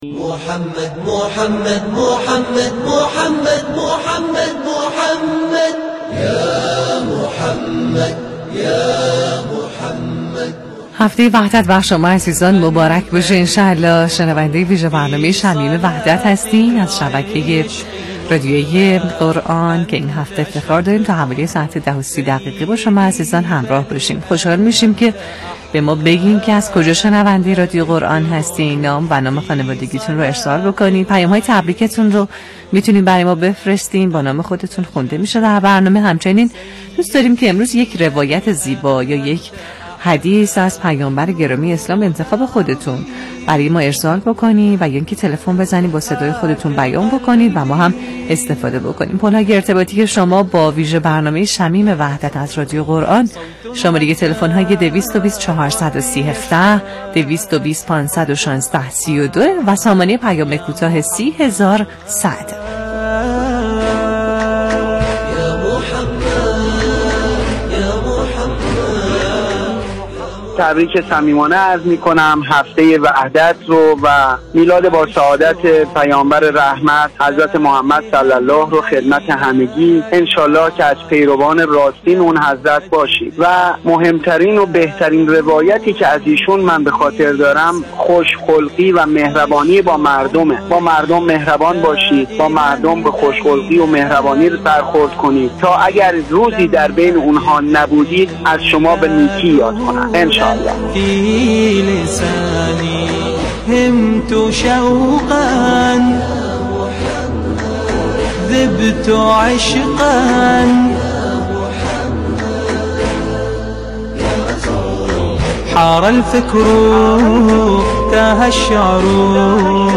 ویژه‌برنامه زنده «شمیم وحدت» طی هفته وحدت از رادیو قرآن پخش می‌شود.
شمیم وحدتبه گزارش ایکنا، شمیم وحدت، عنوان ویژه‌برنامه هفته وحدت شبکه رادیویی قرآن است که به صورت زنده صبح‌ها حوالی ساعت 9:20 تا 10:30 به صورت زنده به روی آنتن این رادیو می‌رود.
این ویژه‌برنامه کاری از گروه اطلاع‌رسانی شبكه راديويی قرآن است که از موج FM رديف ۱۰۰ مگاهرتز تقدیم مخاطبان این رادیو می‌شود.